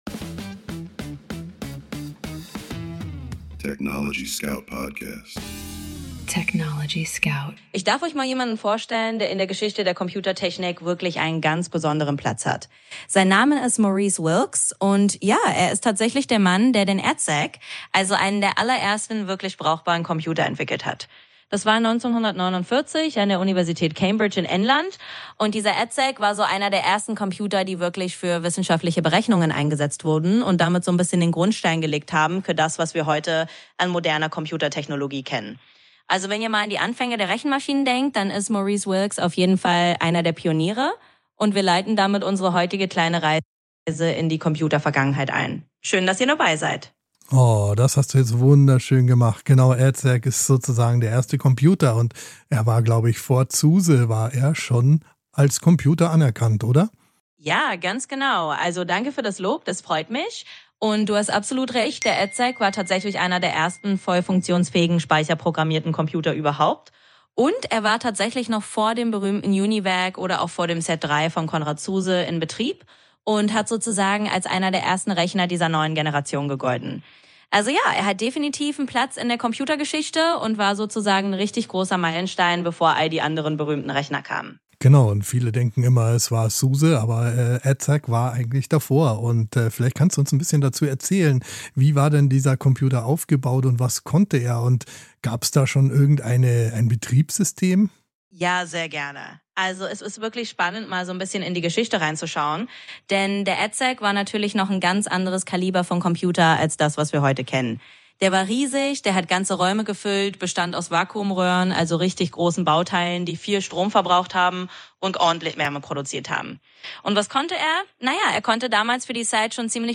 gemeinsam mit seiner digitalen Co-Moderatorin ChatGPT jeden
Mensch und KI sprechen miteinander – nicht gegeneinander.